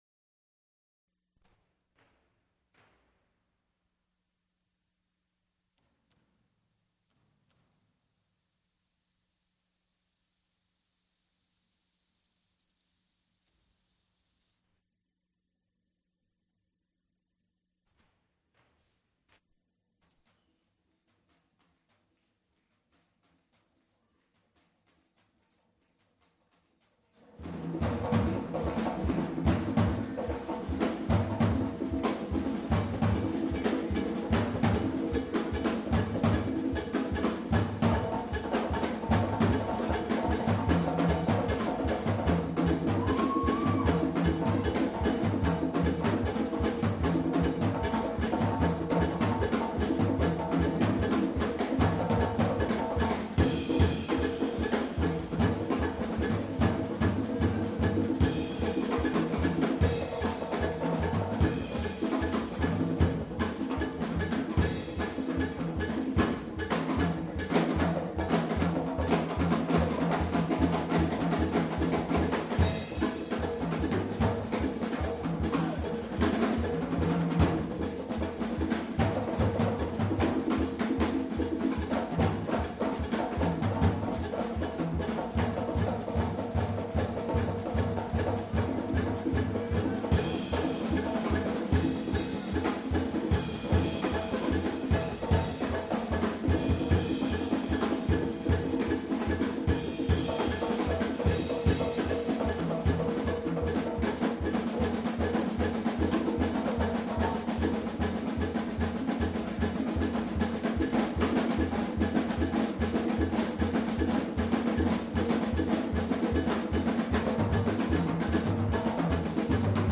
Benny Bailey, trumpet soloist
guitar
drums; Geri Allen, piano; University of Pittsburgh Jazz Ensemble; Nathan Davis, director. Extent 3 audiotape reels : analog, half track, stereo, 7 1/2 ips ; 12 in.
musical performances
Jazz--1981-1990